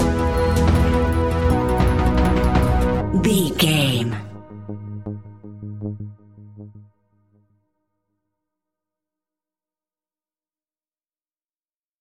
Aeolian/Minor
G#
ominous
dark
eerie
driving
synthesiser
brass
percussion
horror music